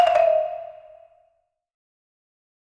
Marimba 3 Notes Descend.wav